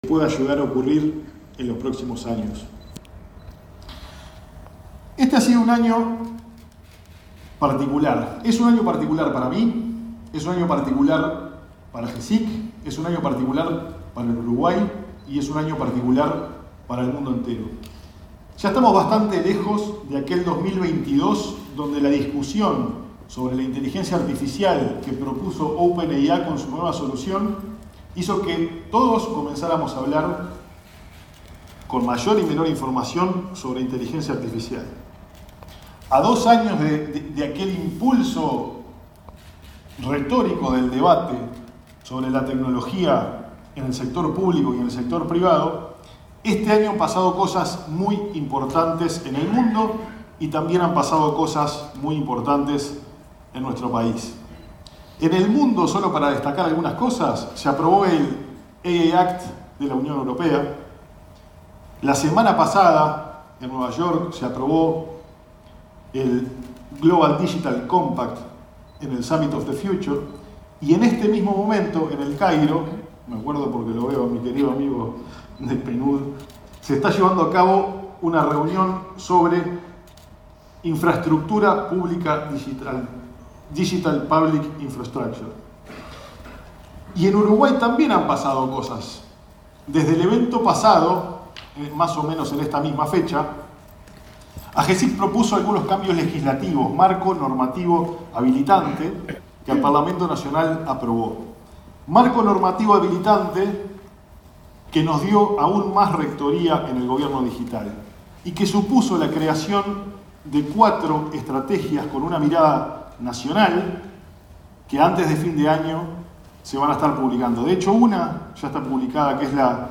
Palabras del director ejecutivo de Agesic, Hebert Paguas
Palabras del director ejecutivo de Agesic, Hebert Paguas 02/10/2024 Compartir Facebook X Copiar enlace WhatsApp LinkedIn Este miércoles 2 en la Torre Ejecutiva, el director ejecutivo de la Agencia de Gobierno Electrónico y Sociedad de la Información y del Conocimiento (Agesic), Hebert Paguas, realizó la apertura del encuentro anual de esa repartición del Estado, en el que se abordaron los hitos de la política digital de Uruguay.